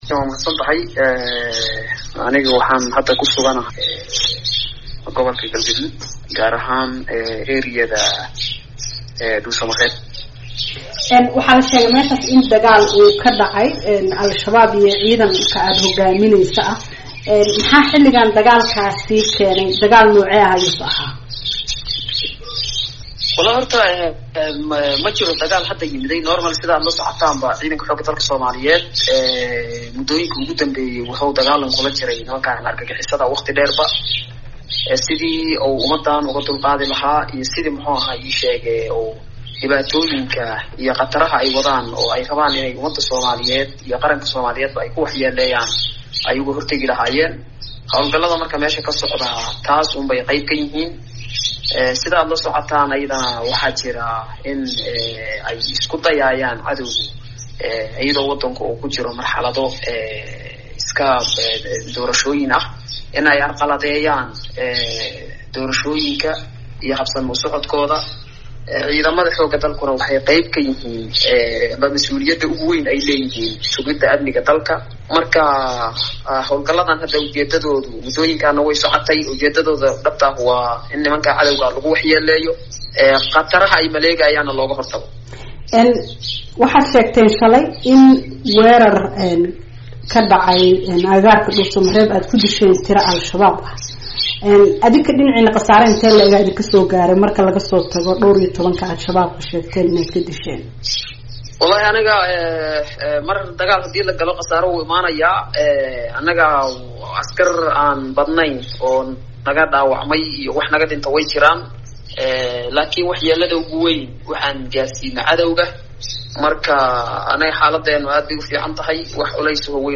Wareysi: Jeneraal Odawaa oo ka hadlay ciidanka la geeyey Beledweyne